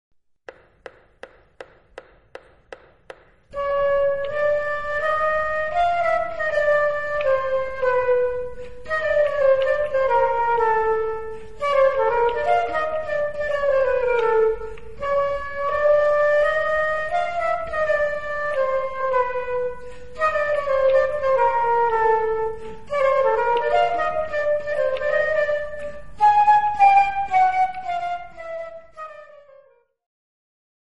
Instrumental Ensembles Flute
A challenge in close harmony playing.
Flute Duet